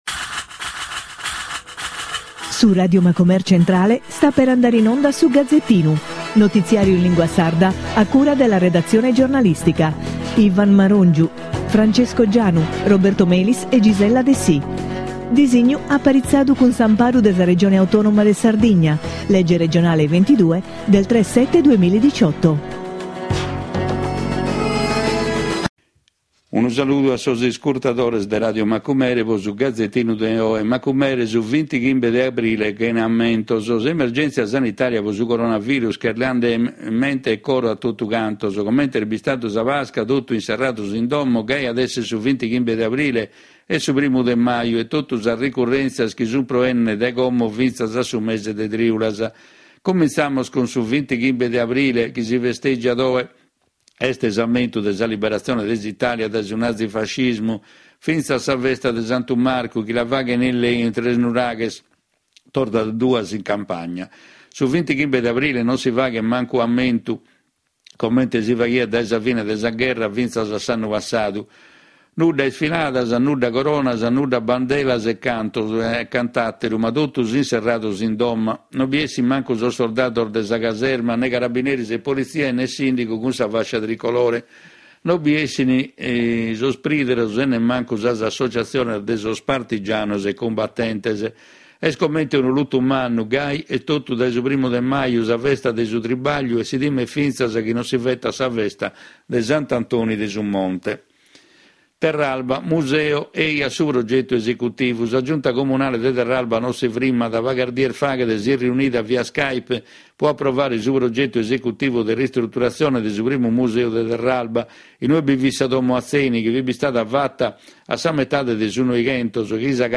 Notiziario in lingua sarda con la consulenza di esperti. Le principali notizie nazionali e nel dettaglio quelle regionali con particolare riferimento all’attività socio economica e culturale della nostra isola con un occhio particolare al mondo dei giovani.